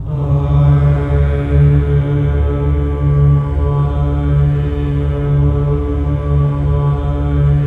VOWEL MV03-R.wav